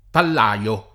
vai all'elenco alfabetico delle voci ingrandisci il carattere 100% rimpicciolisci il carattere stampa invia tramite posta elettronica codividi su Facebook pallaio [ pall #L o ] s. m.; pl. -lai — sim. il cogn. Pallai